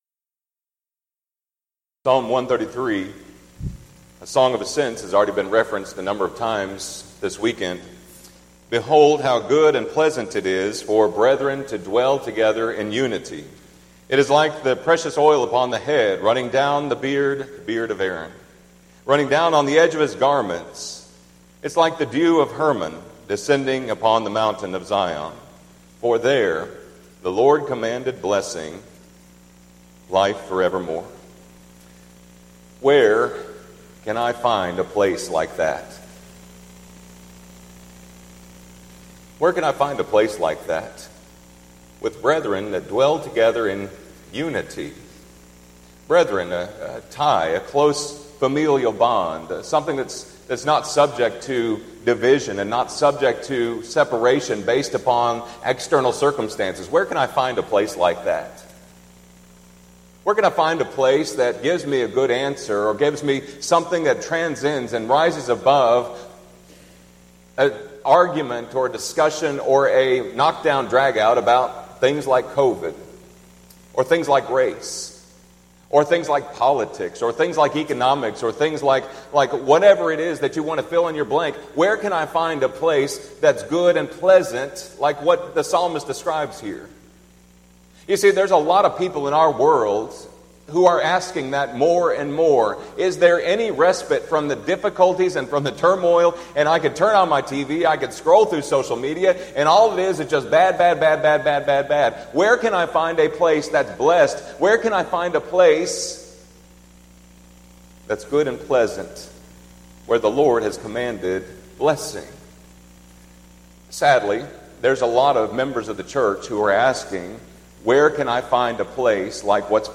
Event: 4th Annual Arise Workshop Theme/Title: Biblical Principles for Congregational Growth
lecture